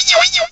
cry_not_snover.aif